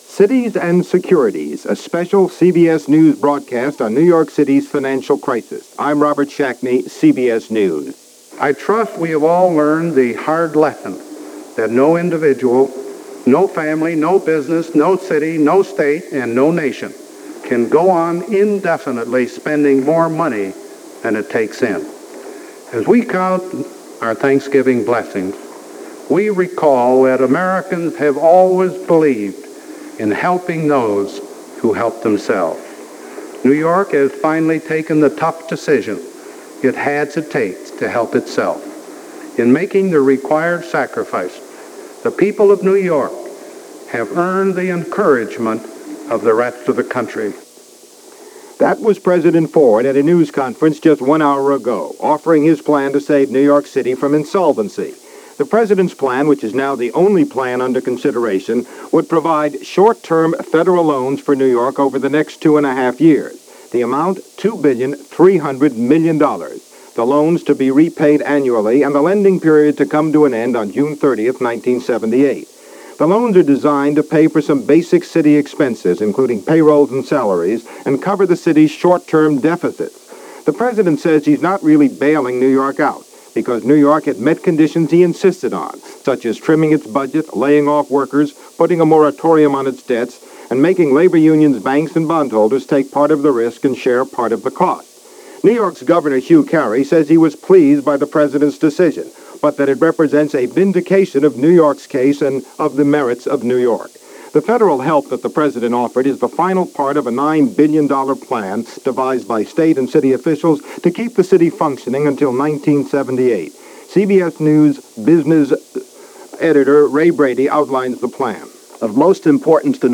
New York Default Crisis - Cavalry Arrives - City Takes Deep Breath - Exhales For Now - November 26, 1975 - CBS Radio Discussion.